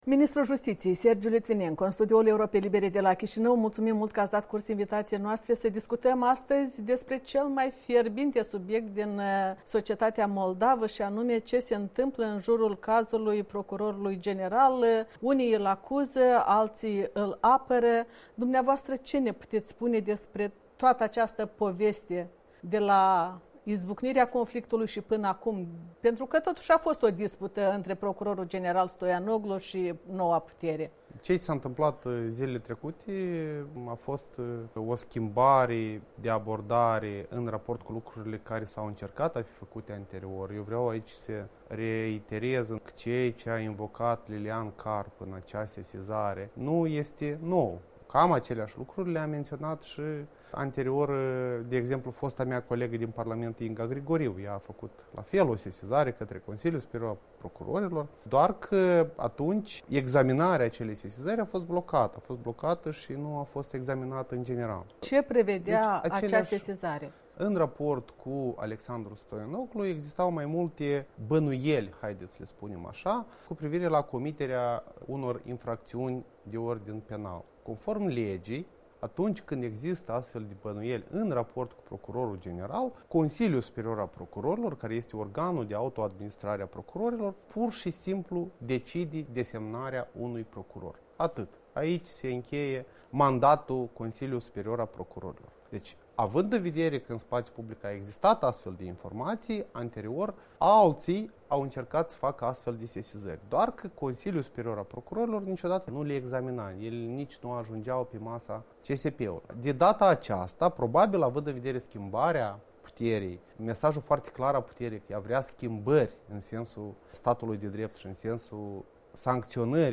Interviu cu ministrul Sergiu Litvinenco